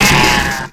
Cri de Kranidos dans Pokémon X et Y.